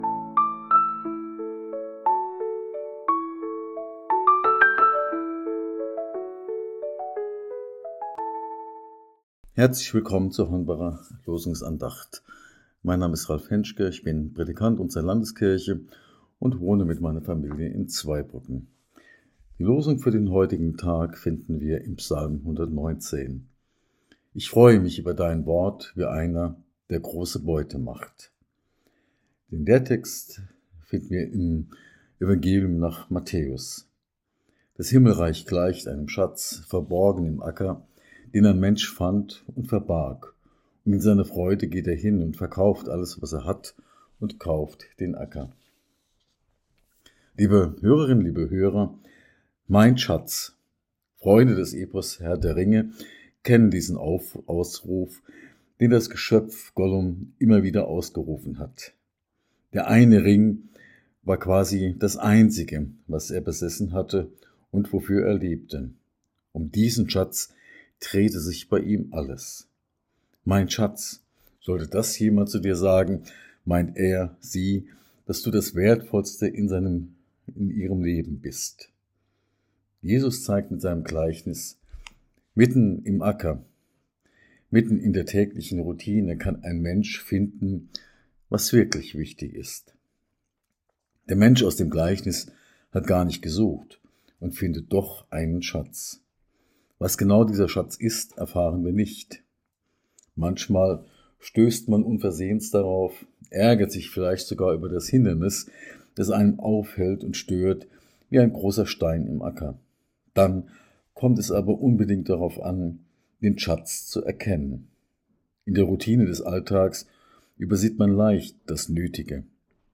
Losungsandacht für Dienstag, 13.05.2025